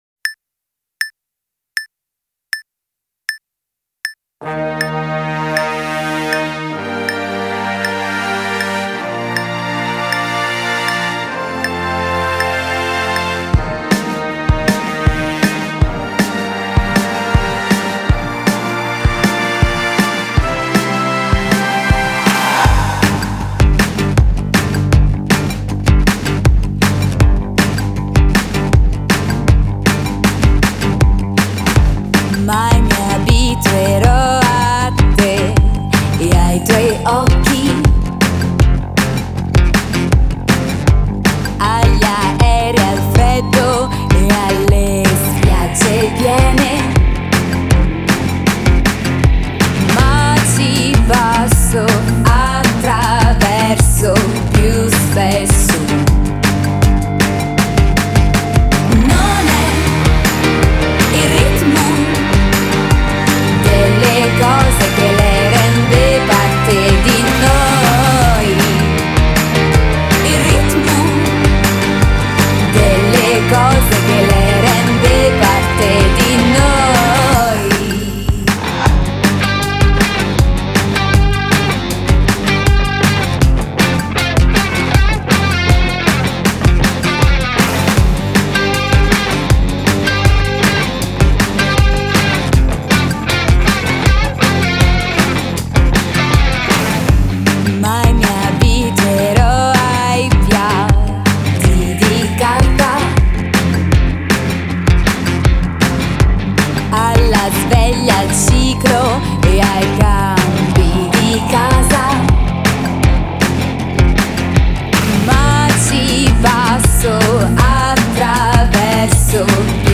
Genre: Pop Rock, Indie